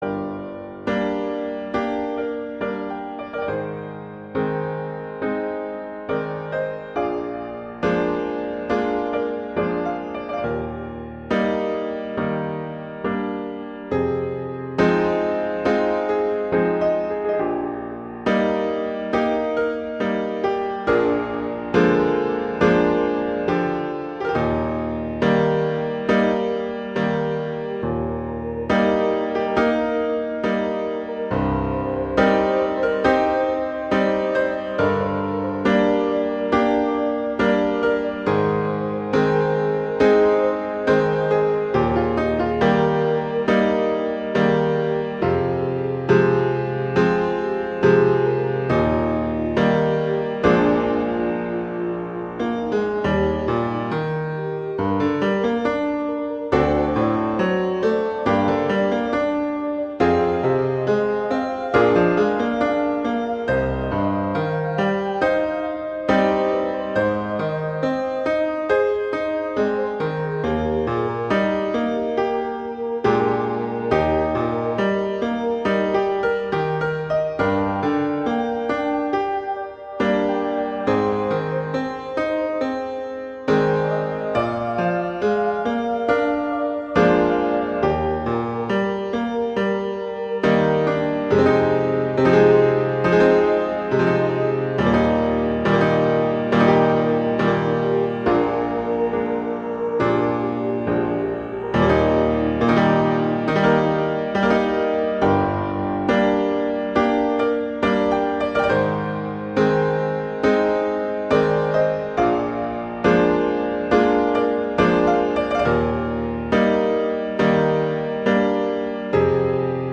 classical, world, children